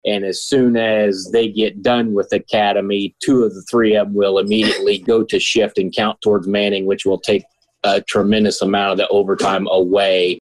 Fire Chief Don McMasters says four firefighters have tested positive for COVID-19 and another is off-work after being exposed to the virus.